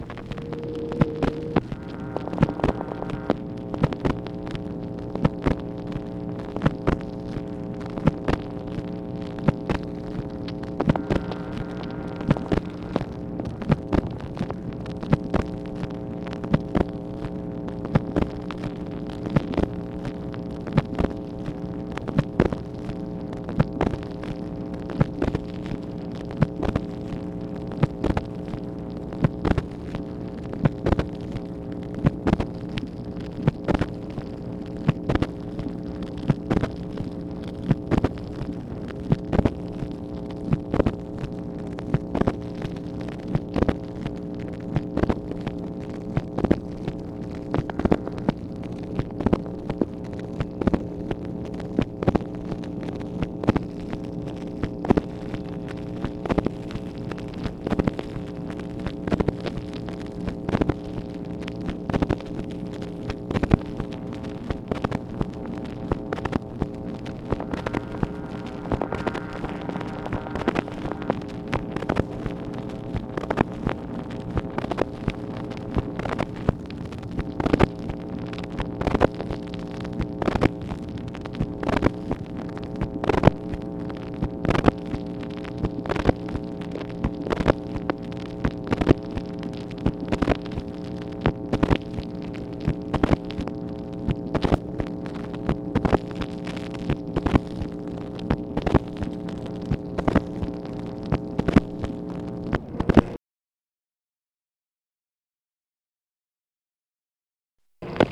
MACHINE NOISE, October 2, 1964
Secret White House Tapes | Lyndon B. Johnson Presidency